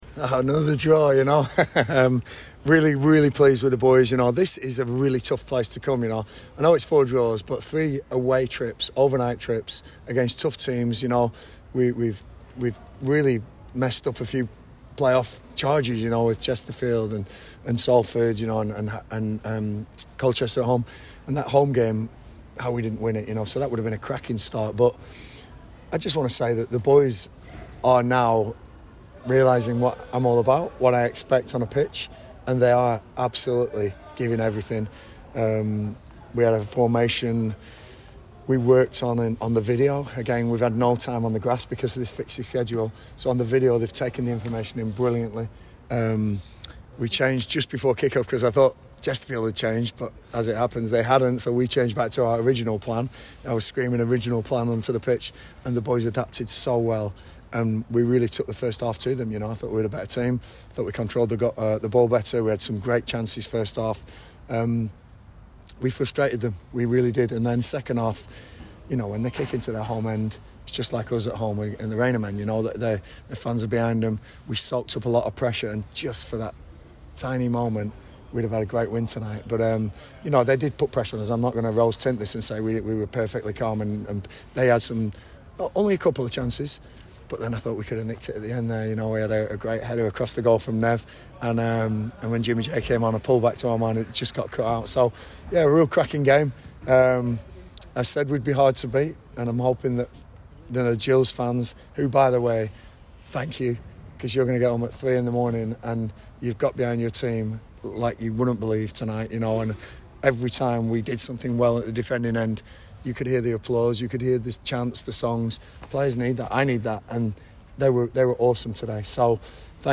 LISTEN: Gillingham manager Gareth Ainsworth has given us his reaction to their 1-1 draw at Chesterfield - 09/04/2025